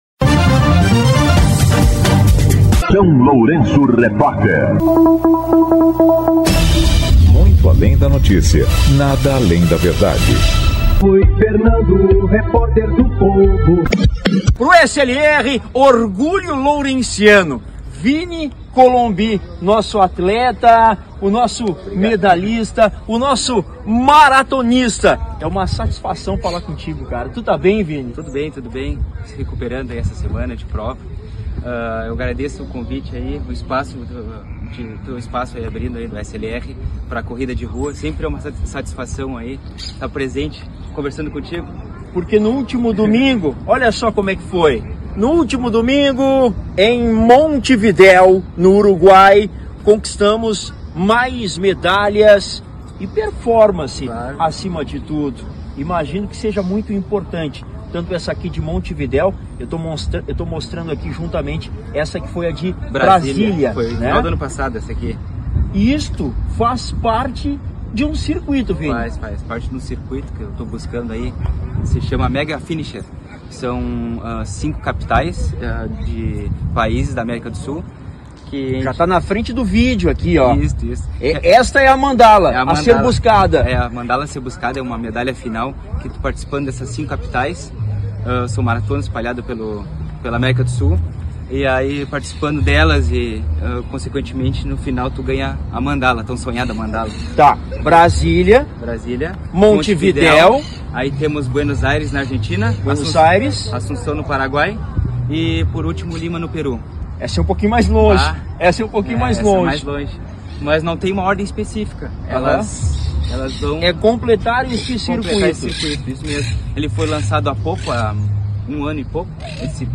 Ao SLR o orgulho lourenciano concedeu entrevista exclusiva